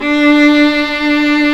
Index of /90_sSampleCDs/Roland L-CD702/VOL-1/STR_Viola Solo/STR_Vla1 % + dyn